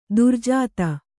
♪ durjāta